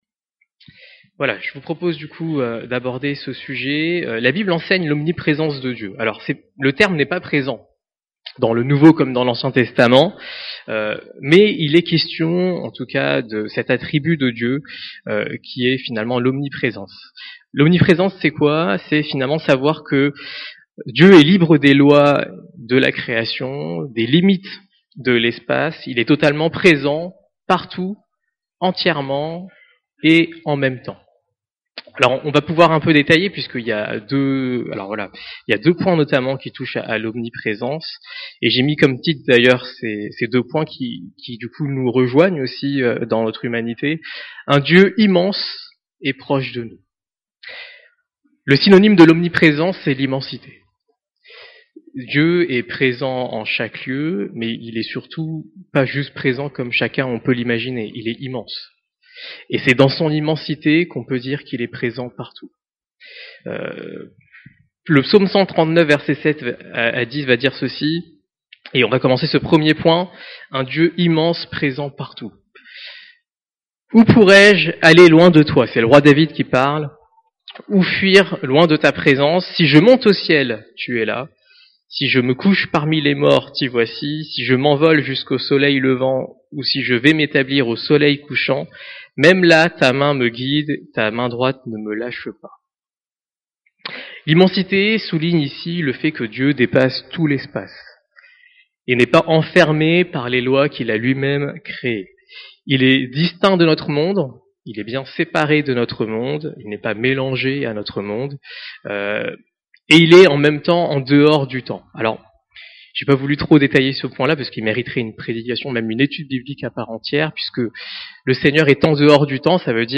Dieu immense et proche de nous - Il est omniprésent (les attributs de Dieu) - Eglise Vie Nouvelle à Saumur